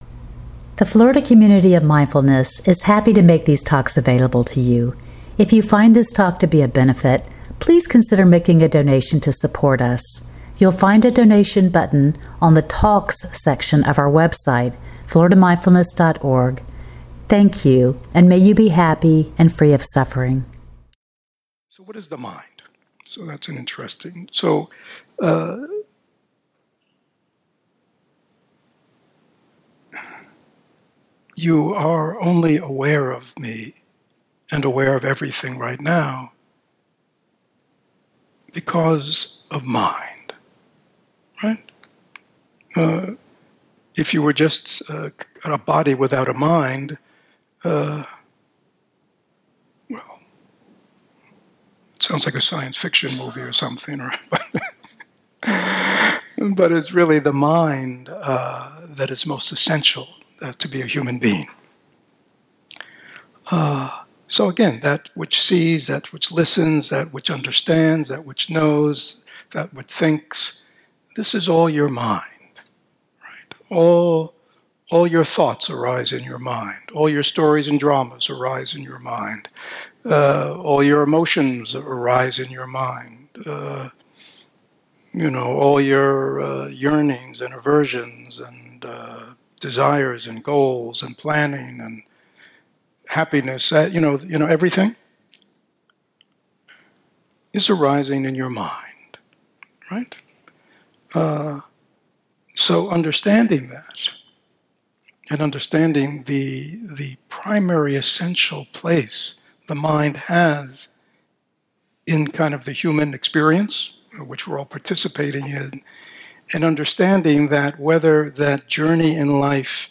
In this talk